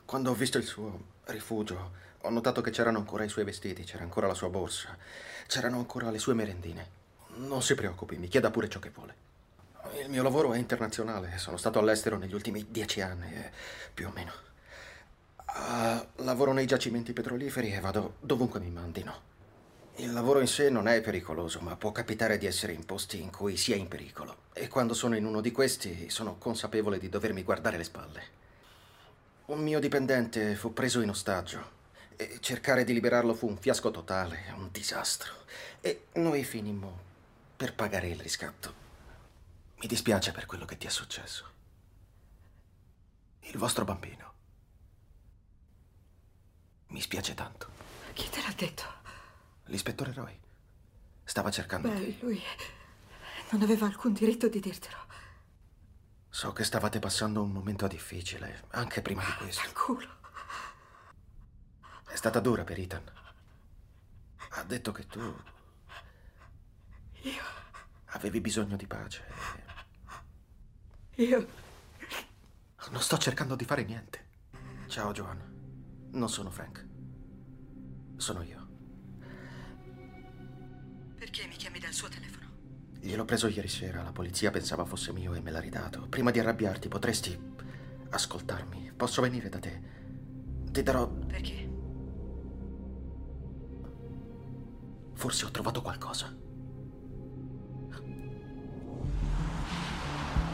Il mondo dei doppiatori
in cui doppia James McAvoy.